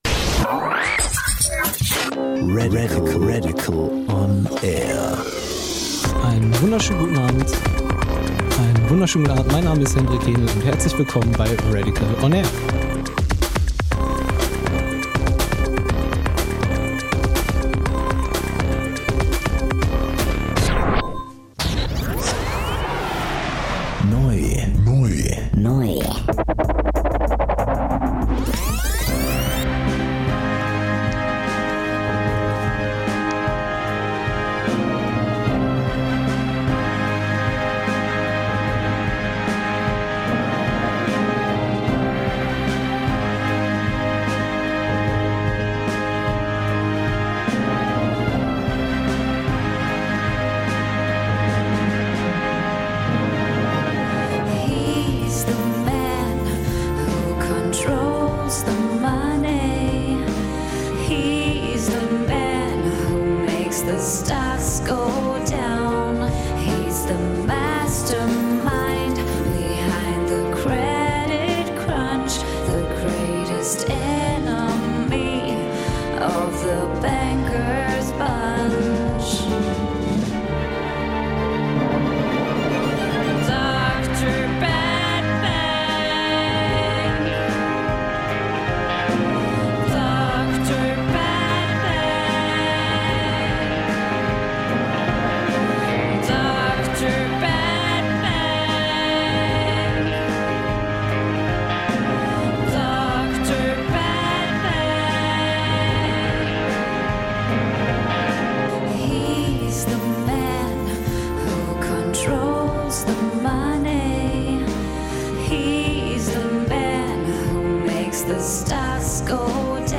Studiogast